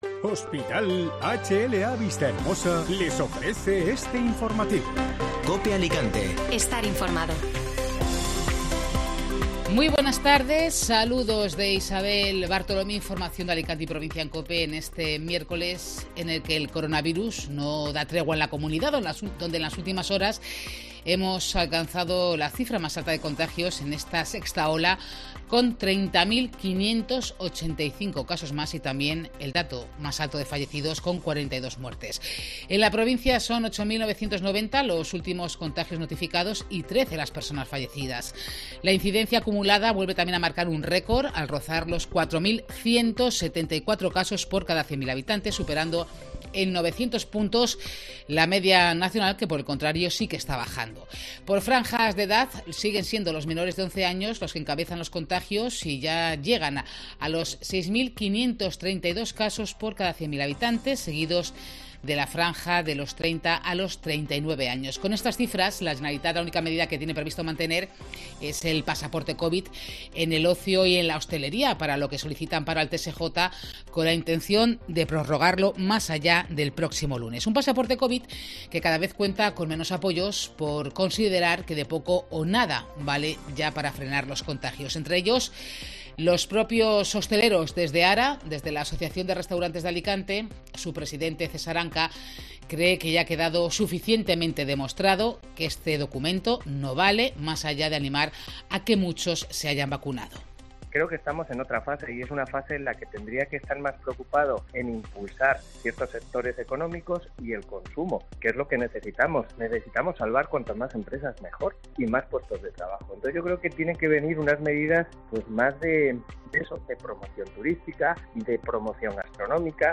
Informativo Mediodía COPE (Miércoles 26 de enero)